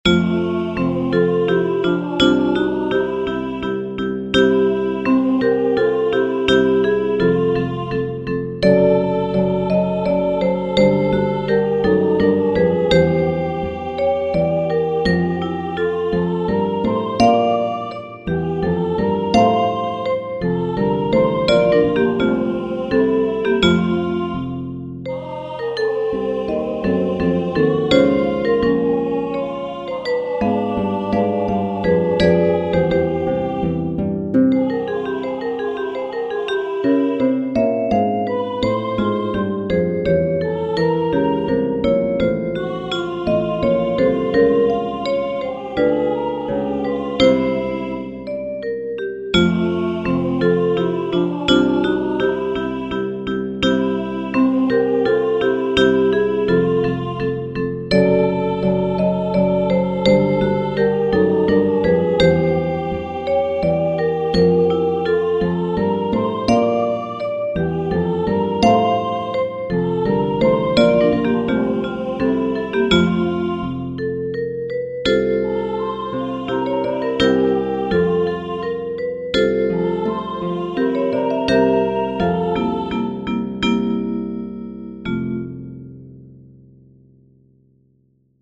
Quand une voix est mise en évidence, elle est jouée sur "Aah", les autres voix sont jouées au vibraphone.
Leur rendu sonore est également meilleur que celui de la page web.